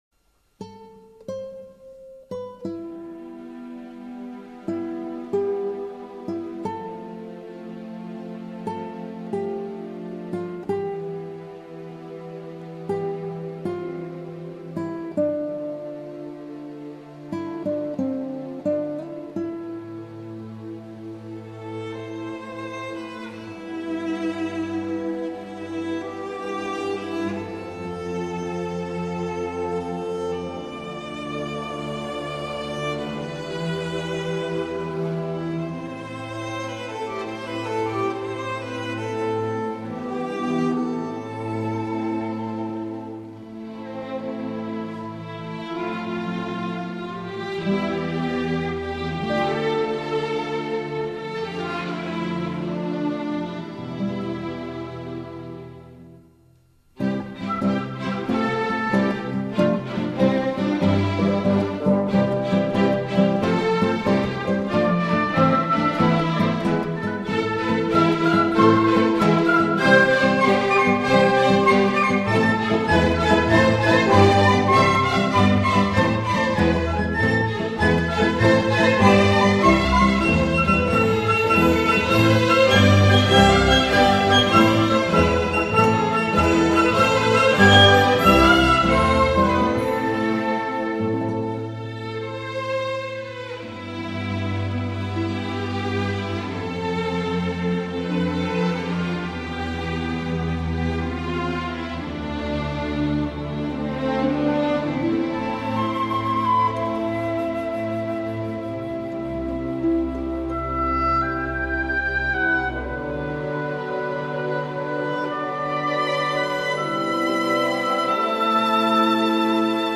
آهنگ بی کلام